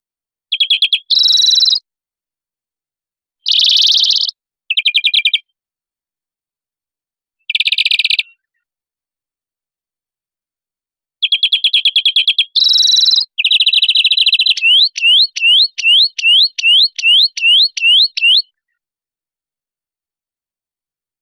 Gesang Grünfink
• Sein Gesang klingt flötend und kehlig – oft schon ab Februar hörbar.
Der Gesang des Grünfinks ist flötend, manchmal etwas nasal und oft rau oder rollend.
Typisch sind klingende Triller, ein langgezogenes „düühüüüh“ oder „dschreee“, oft kombiniert mit kurzen, wiederholten Tonfolgen.
• Gesang (Balz/Markierung): flötend, rollend, „düh-düh-düh-dschree
Gesang-Gruenfink-Voegel-in-Europa.wav